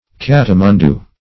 Search Result for " kattimundoo" : The Collaborative International Dictionary of English v.0.48: Kattimundoo \Kat`ti*mun"doo\, n. A caoutchouc-like substance obtained from the milky juice of the East Indian Euphorbia Kattimundoo .